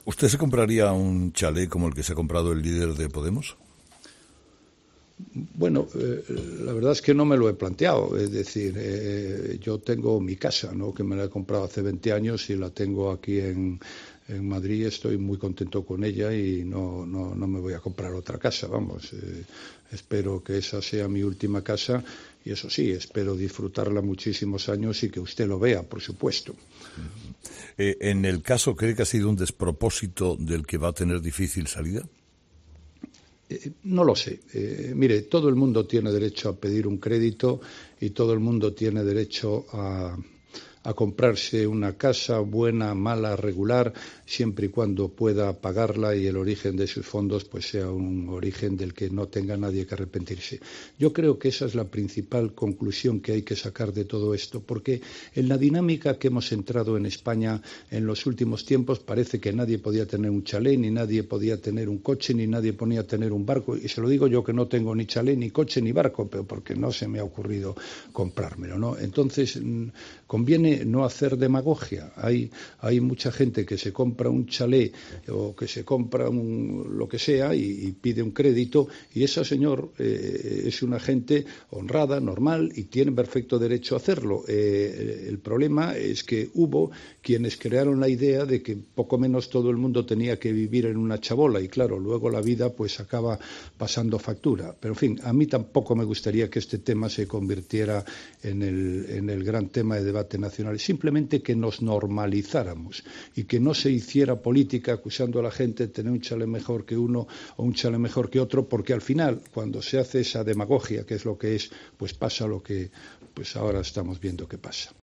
El presidente del Gobierno, Mariano Rajoy, ha admitido en 'Herrera en COPE' que no le gustaría que se hiciese un "gran debate nacional" sobre el chalé de Pablo Iglesias e Irene Montero, si bien ha señalado que "cuando se hace demagogia" sobre la forma de vivir después "pasa lo que pasa".